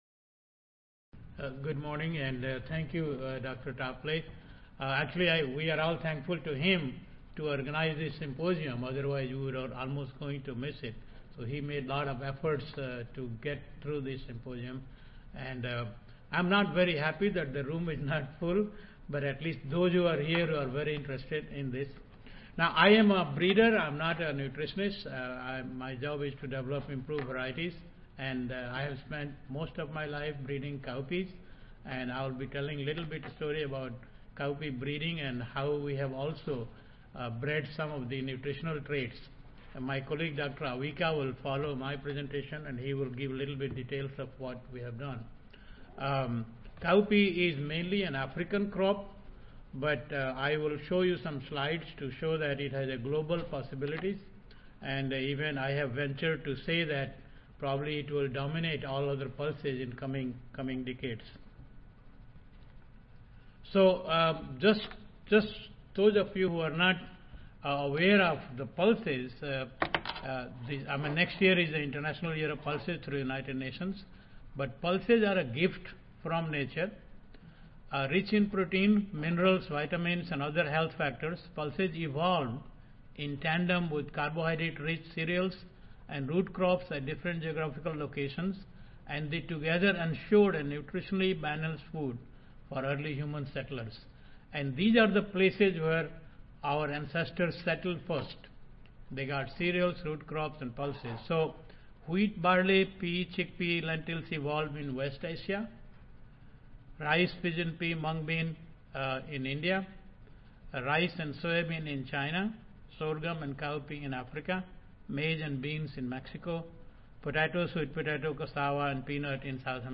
Texas A&M University and G.B. Pant University of Agriculture & Technology Audio File Recorded Presentation